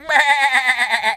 sheep_baa_bleat_high_04.wav